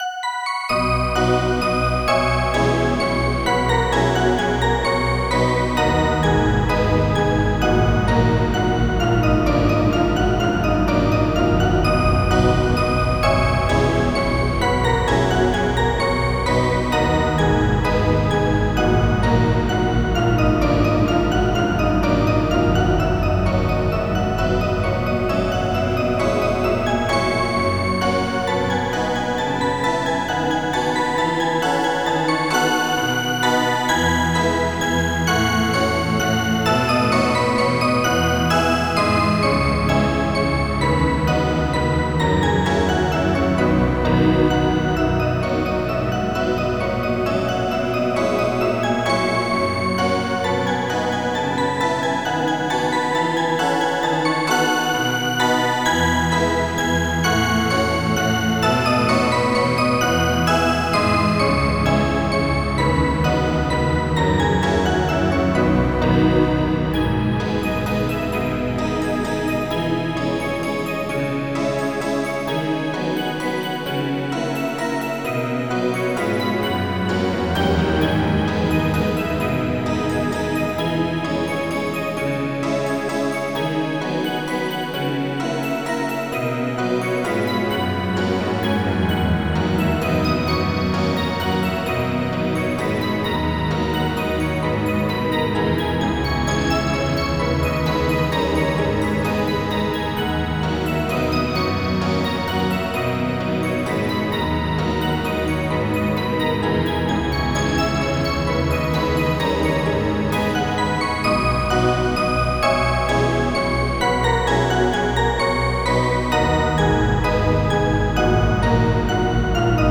MIDI Music File
walzer.mp3